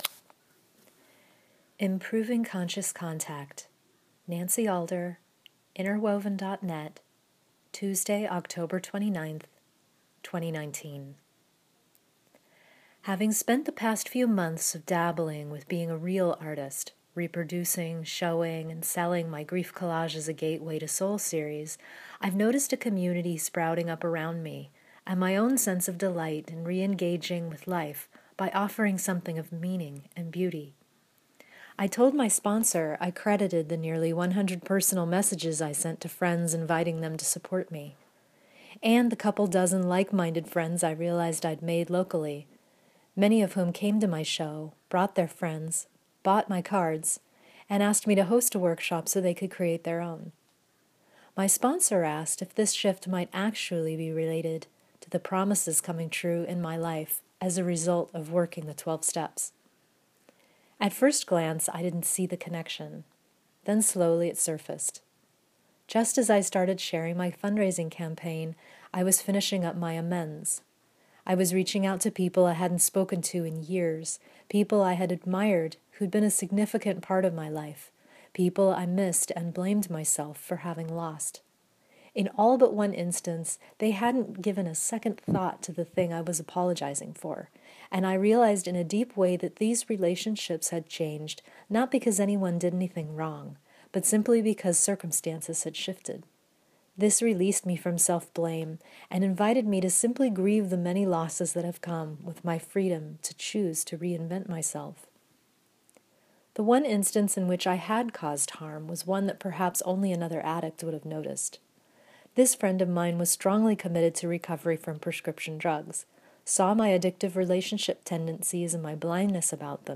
No time to sit down for this 9-minute read? Let me read it to you on your next walk or commute!